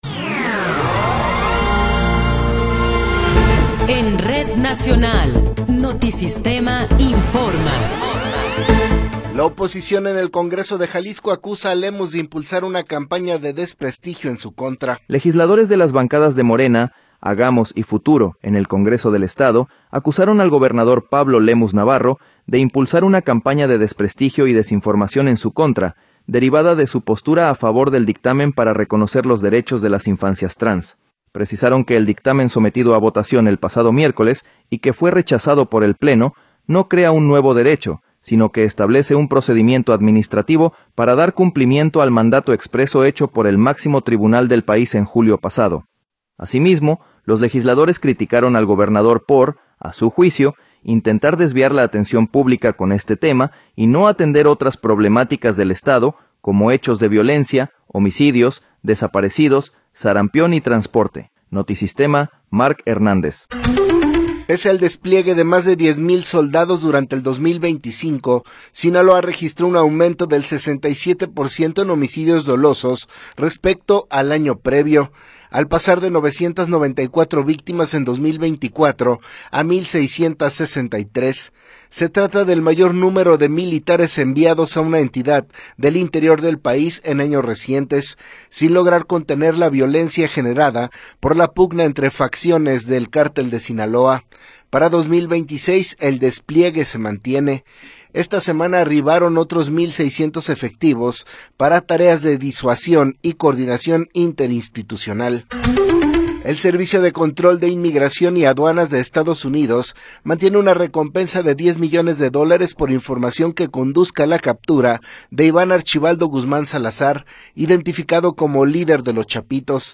Noticiero 11 hrs. – 31 de Enero de 2026
Resumen informativo Notisistema, la mejor y más completa información cada hora en la hora.